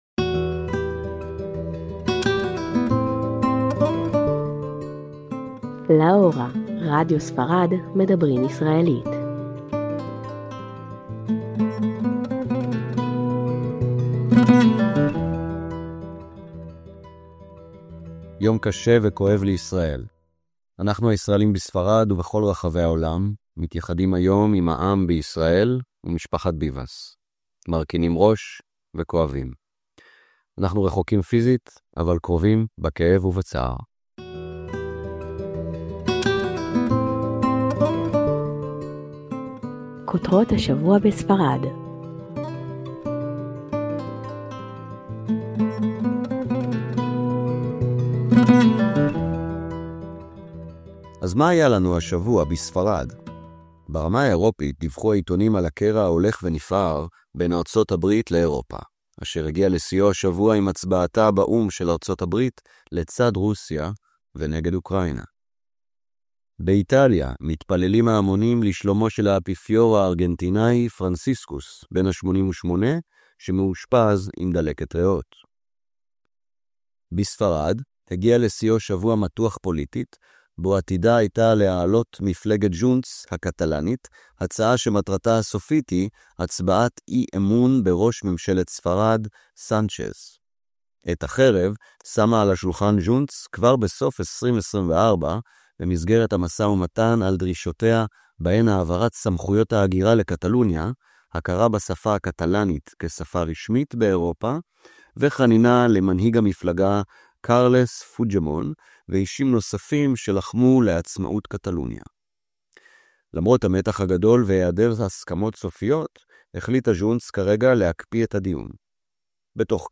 LA HORA: MEDABRÍM ISRAELÍT - לה הורה״ - מיזם רדיו לטובת הישראלים בספרד.